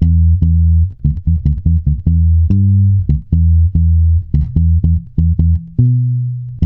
-MM RAGGA E.wav